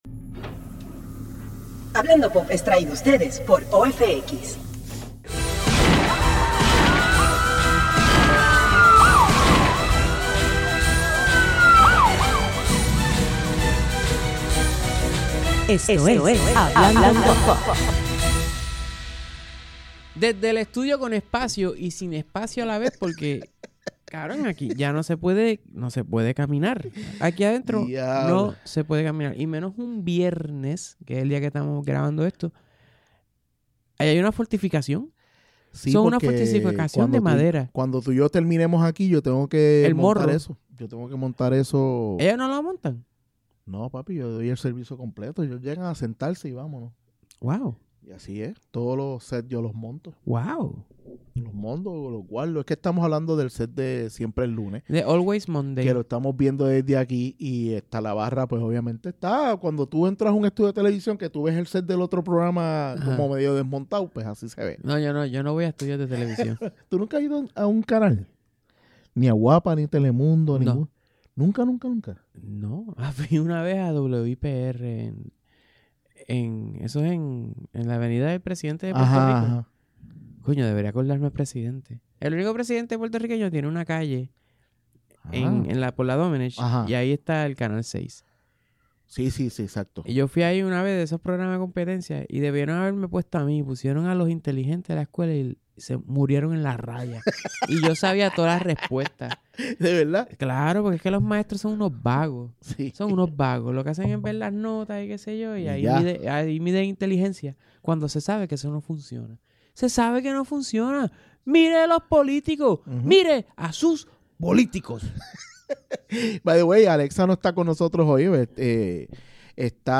Este episodio salio como se pudo por que con tantas tormentas y problemas de agenda termino como un episodio unplugged.